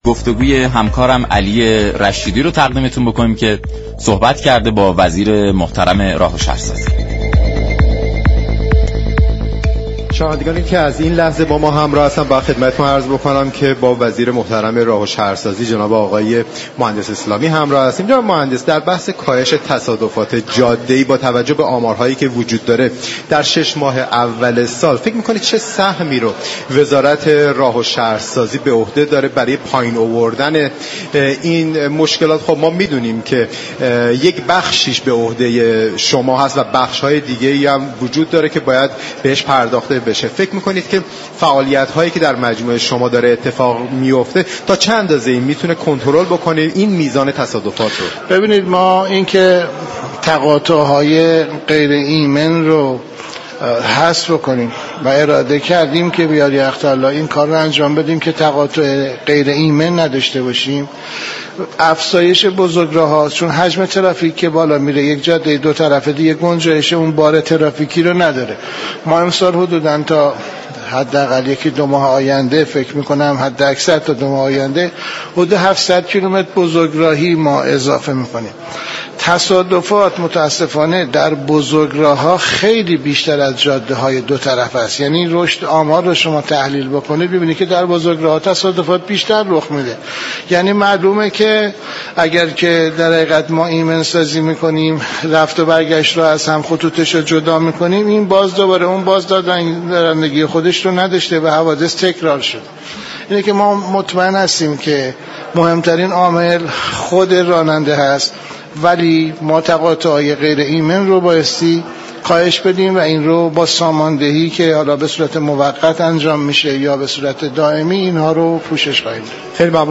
وزیر راه و شهرسازی در گفت و گوی اختصاصی با رادیو ایران گفت: با آنكه شخص راننده در بروز تصادفات نقش مهم و تعیین كننده دارد، اما وزارت راه و شهرسازی در جهت ایمن سازی جاده ها تلاش می كند با كاهش تقاطع های غیر ایمن گام موثری داشته باشد
این گفت و گو را در ادامه باهم می شنویم.